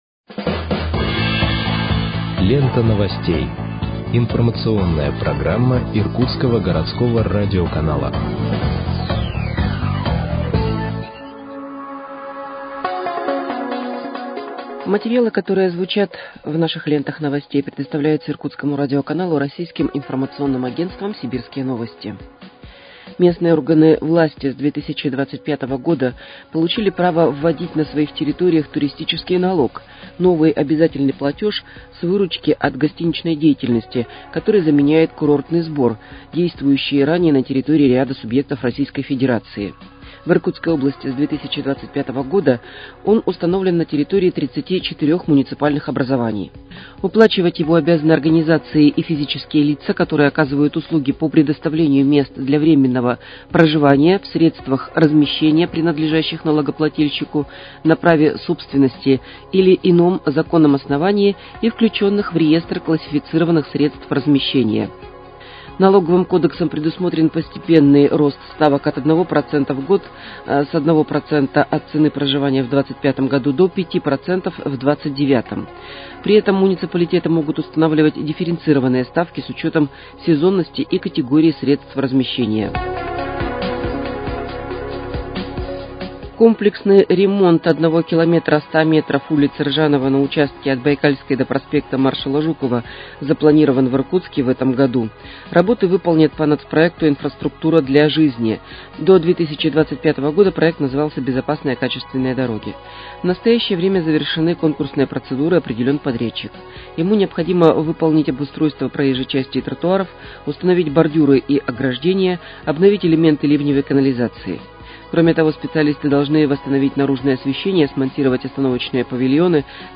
Выпуск новостей в подкастах газеты «Иркутск» от 16.01.2025 № 2